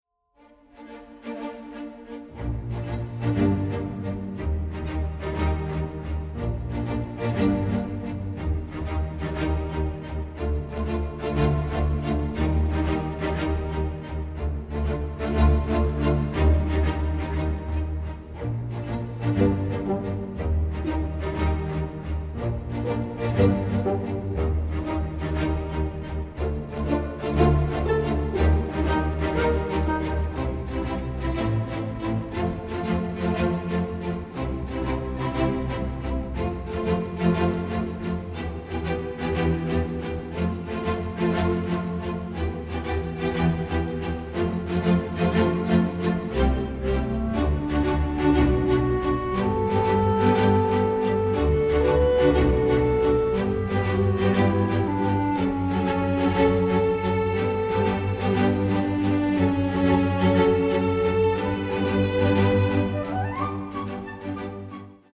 symphonic, varied, funny and emotional